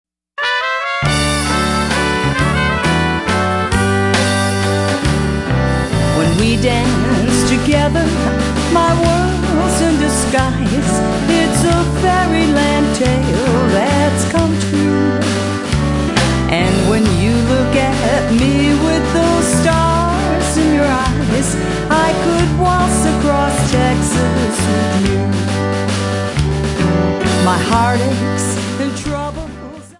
Polkas, Waltzes and Obereks